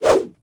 footswing9.ogg